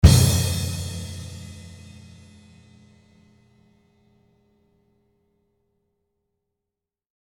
Hlásíme do světa HOTOVO! 23 (dvacettři) mikrofonů do 23 (dvacetitří) stop je za náma.
Zvuk je hodně plný.